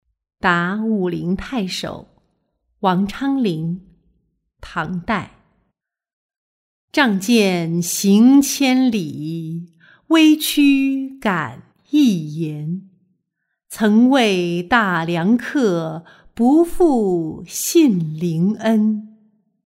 答武陵太守-音频朗读